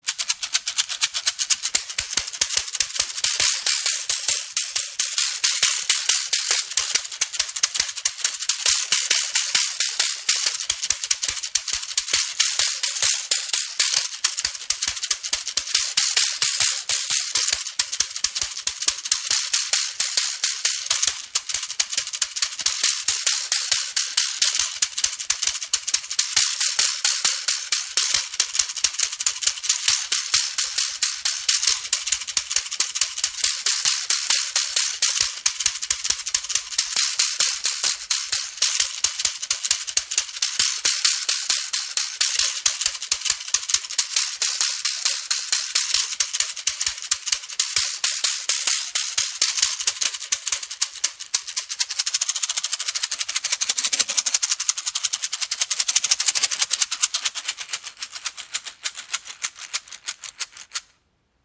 Rassel | Flamboyant Seed Shaker im Raven-Spirit WebShop • Raven Spirit
Klangbeispiel
Geschüttelt oder auf die Handballen geschlagen entsteht ein angenehmes, rhythmisches Klappern, welches in vielen Kulturen bei Zeremonien, Festen oder bei traditionellen Tänzen eingesetzt wird - natürlich durch unerschiedlichste Rasseln.
Rassel | Flamboyant Seed Shaker Die Samen Shaker können sanft aber auch Rhythmisch gespielt werden.